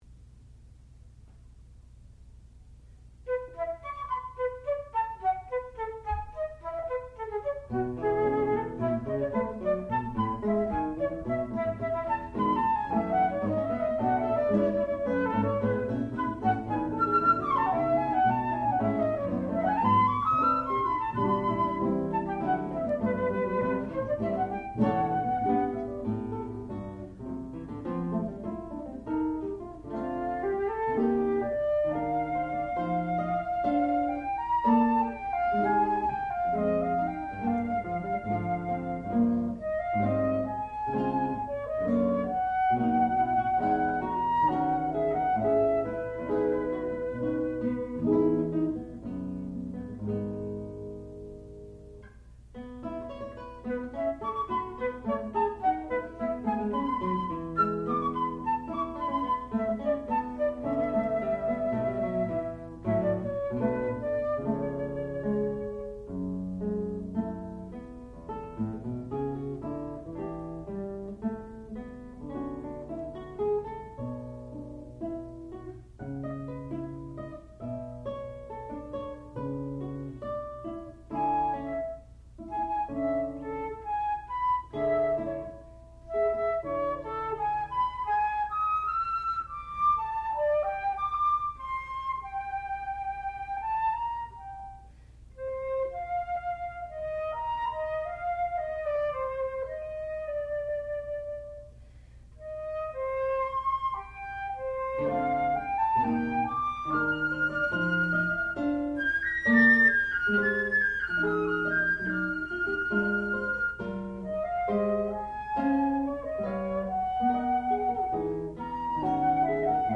Four Excursions for Guitar and Flute (1971)
classical music
Advanced Recital